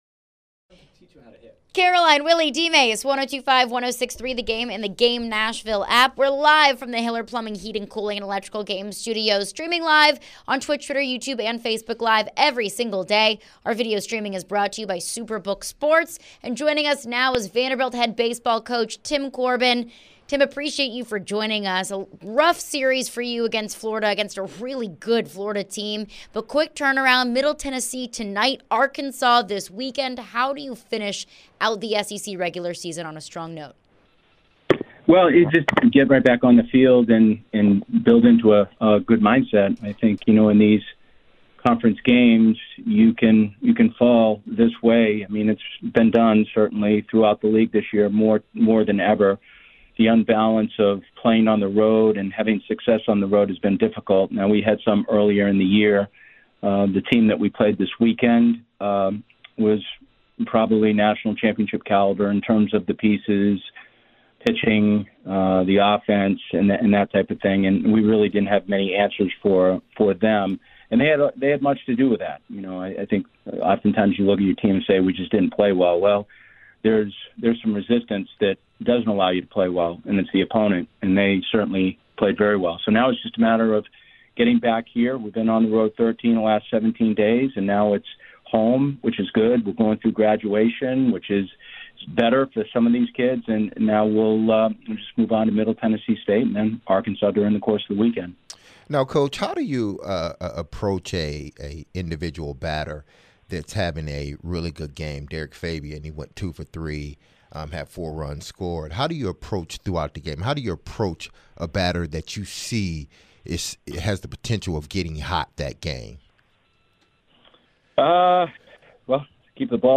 Tim Corbin Interview (5-16-23)